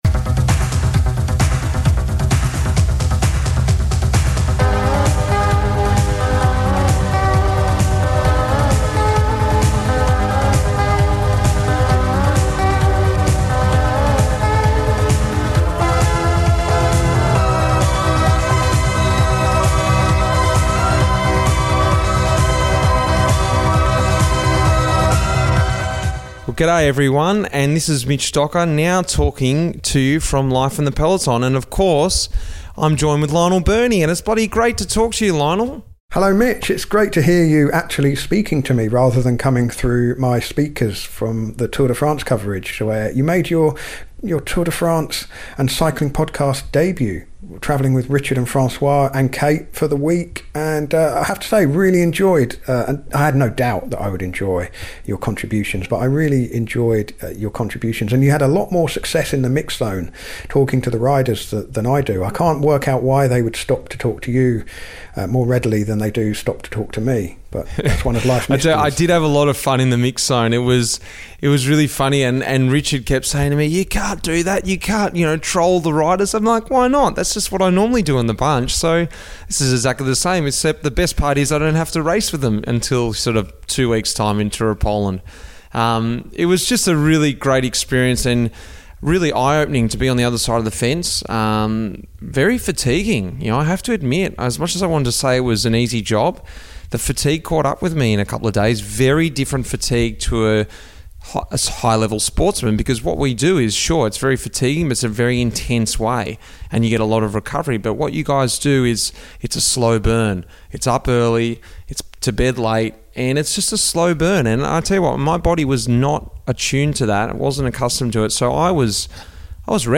Life in the Peloton is back with a chat with my teammate Magnus Cort Nielsen, who talks through his journey from growing up on one of Denmark's islands to winning stages in the Tour de France and the Vuelta a España.